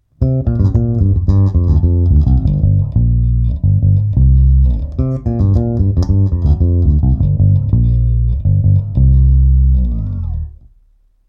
Aparát jsem snímal z přibližně půlmetrové vzdálenosti studiovým kondenzátorovým mikrofonem Joemeek JM 37.
Všechny nahrávky jsou bez dodatečných úprav. Neodstraňoval jsem šum, neupravoval ekvalizaci, jen jsem nahrávky znormalizoval.
Nejprve sem hodím dvě ukázky sejmuté mikrofonem: